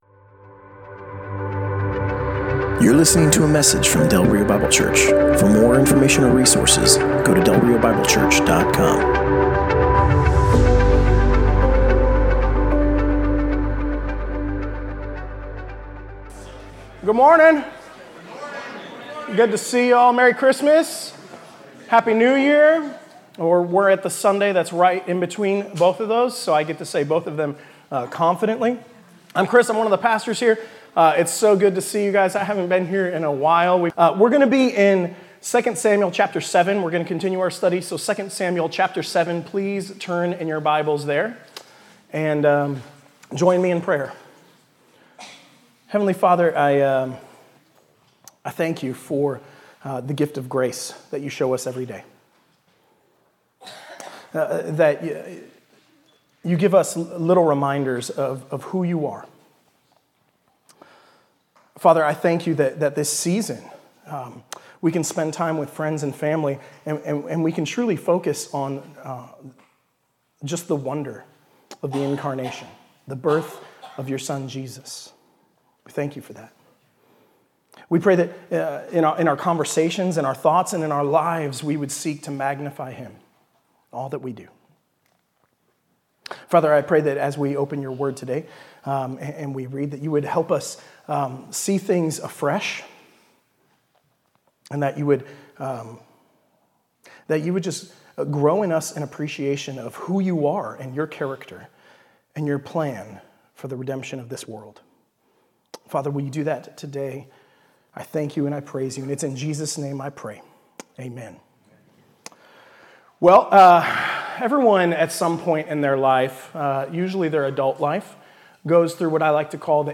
Passage: 2 Samuel 7:1-29 Service Type: Sunday Morning